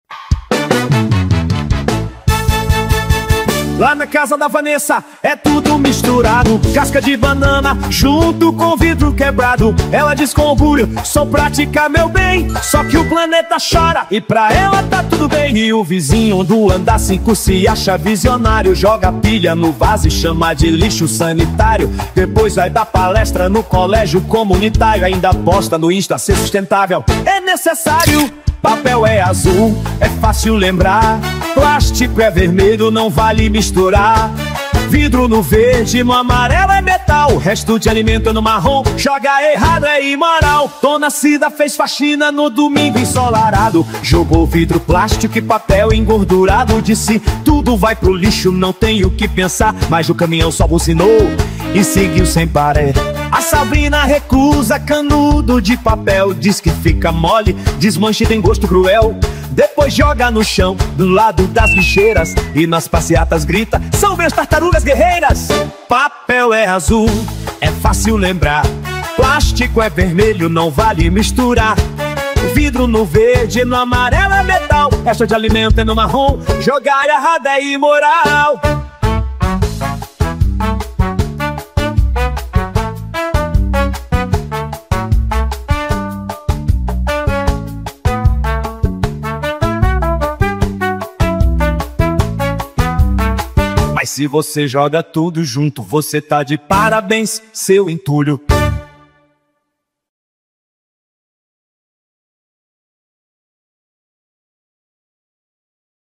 No ritmo contagiante do axé